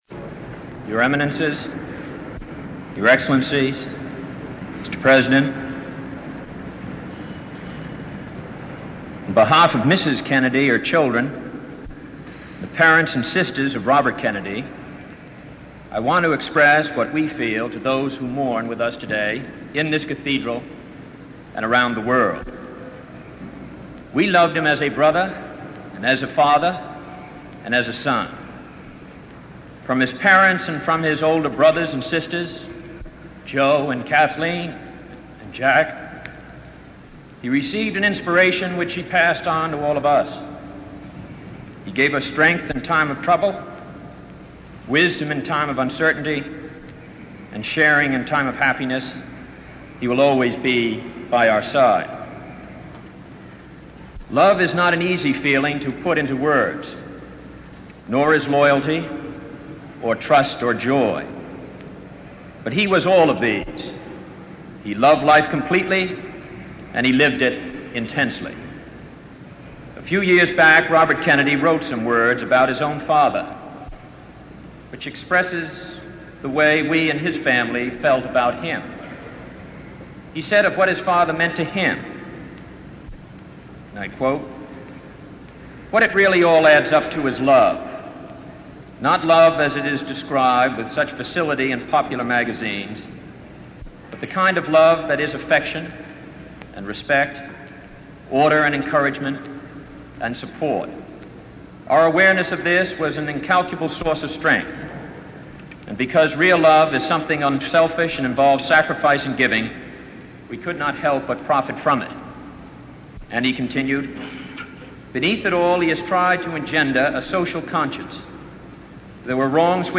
Address at the Public Memorial Service for Robert F. Kennedy
delivered 8 June 1968 at St. Patrick's Cathedral, New York